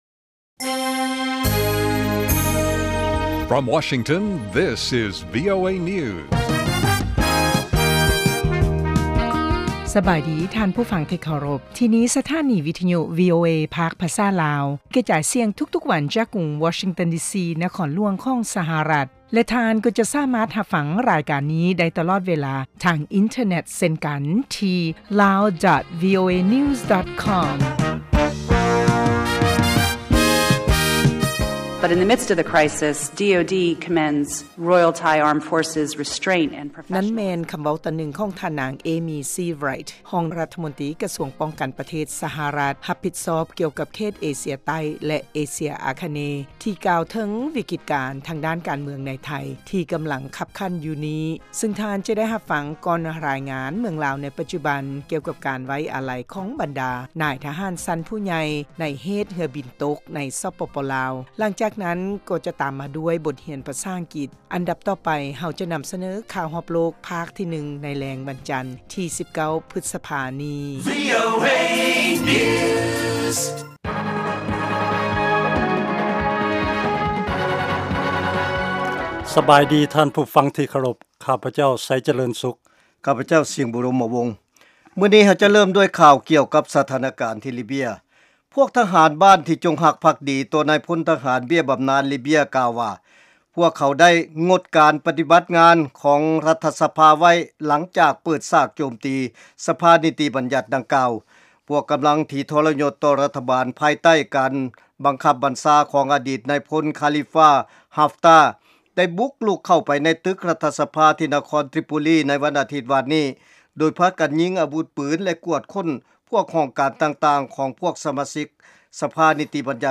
ລາຍການກະຈາຍສຽງຂອງວີໂອເອ ລາວ